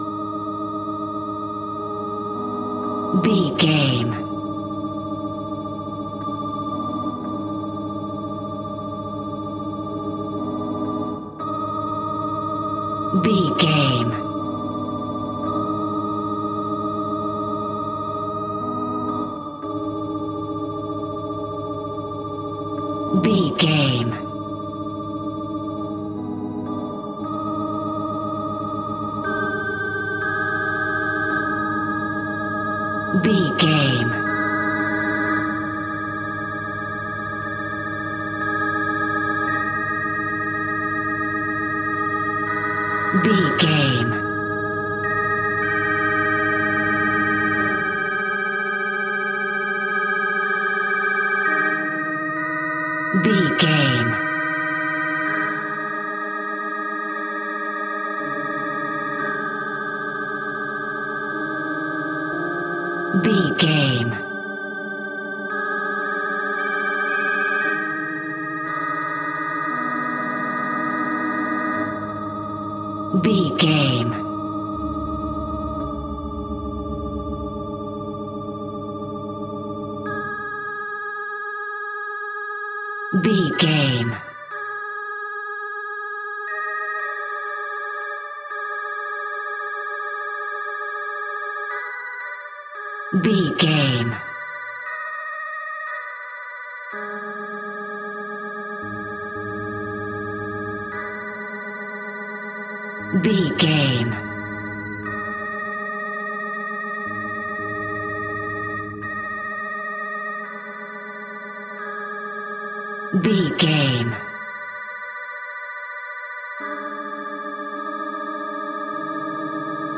Twisted Horror Pipe Organ.
Aeolian/Minor
G#
Slow
ominous
haunting
eerie
synthesiser
electric organ
Church Organ